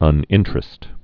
(ŭn-ĭntrĭst, -tər-ĭst, -trĕst)